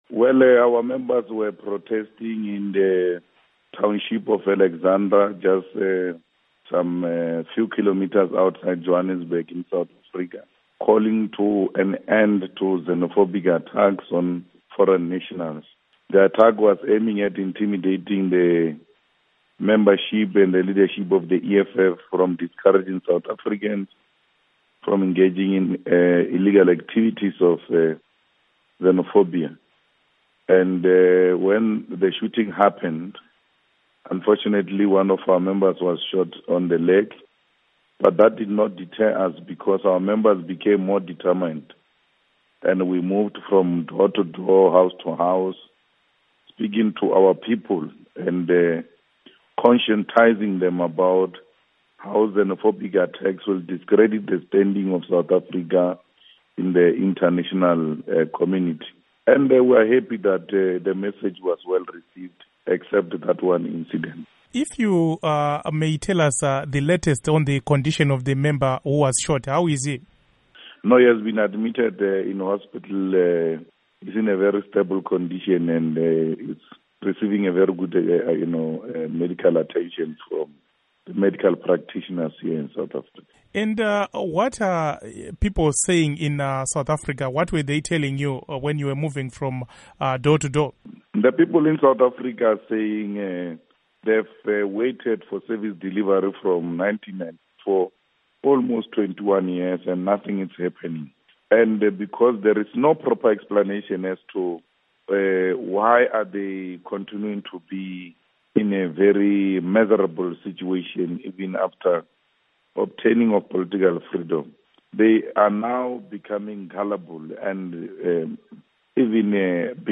Interview With Julius Malema